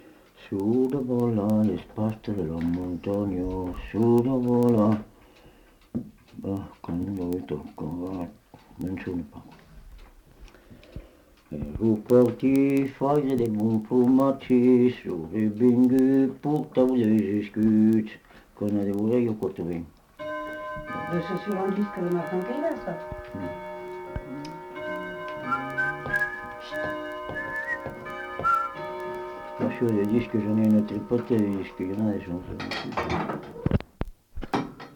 Aire culturelle : Viadène
Genre : chant
Effectif : 1
Type de voix : voix d'homme
Production du son : chanté
Classification : danses